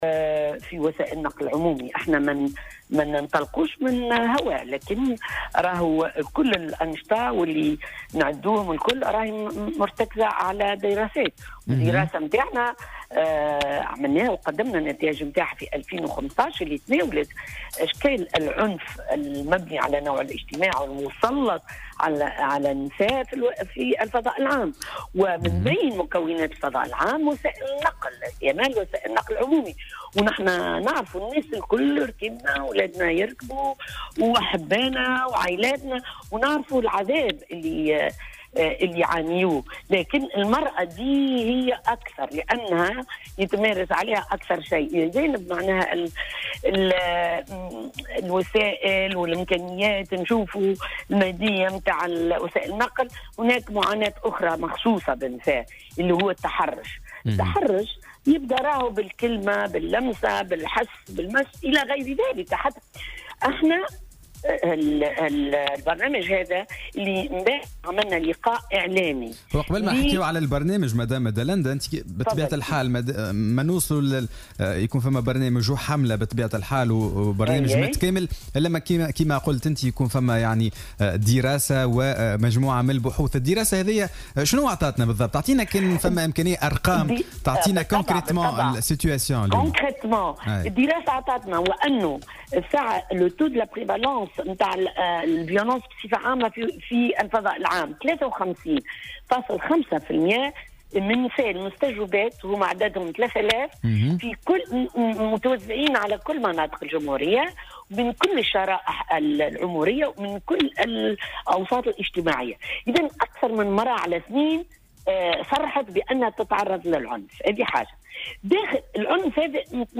في مداخلة لها اليوم في برنامج "صباح الورد"